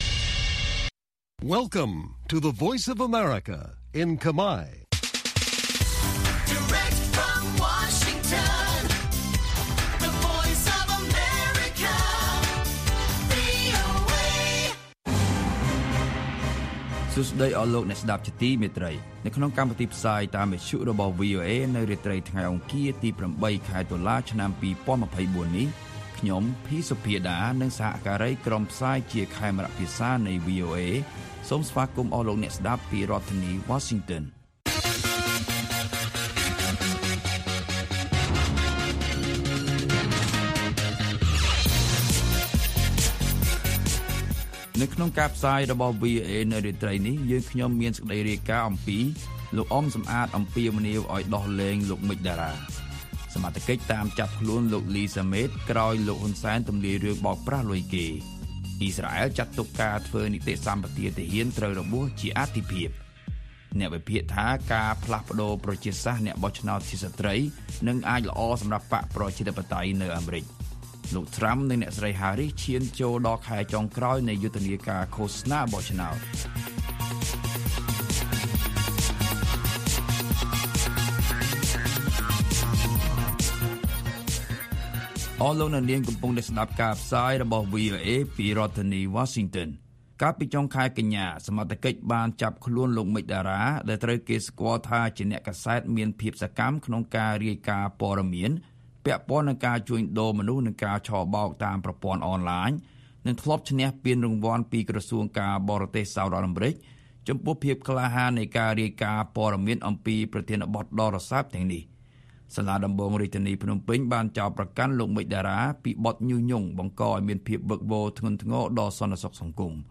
ព័ត៌មានពេលរាត្រី
បទសម្ភាសន៍